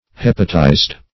Hepatize \Hep"a*tize\, v. t. [imp.